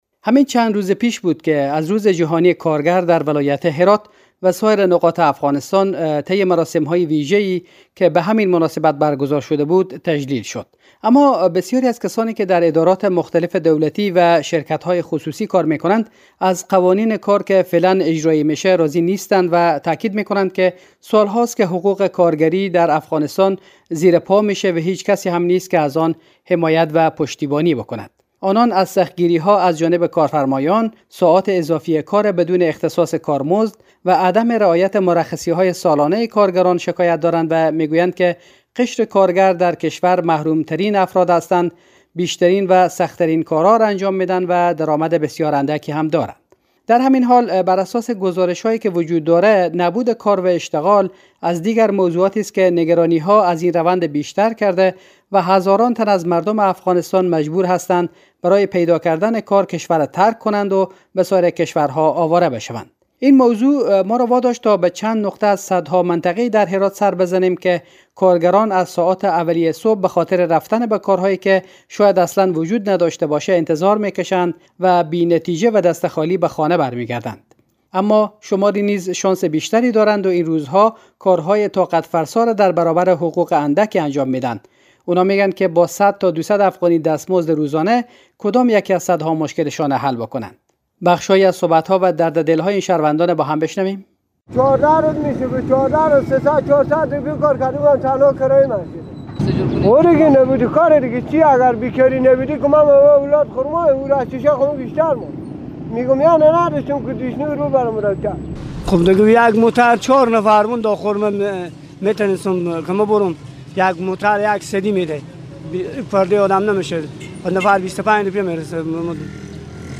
خبر